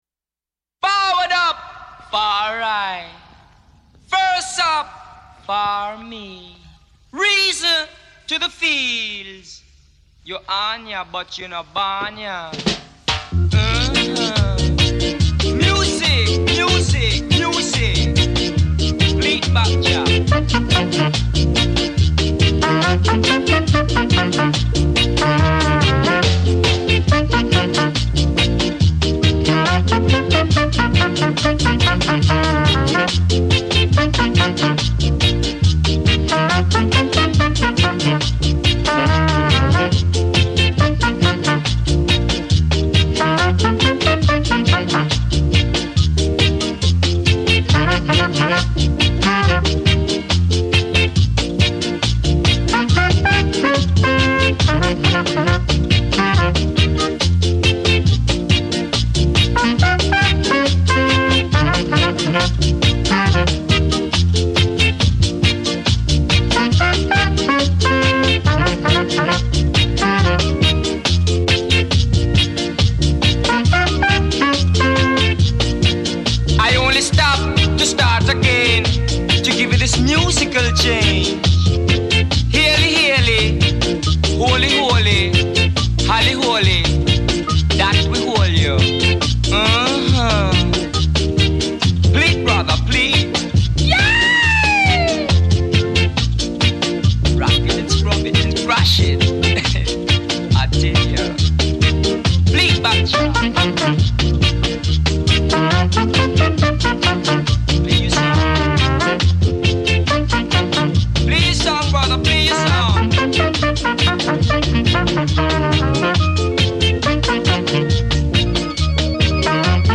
Genre Reggae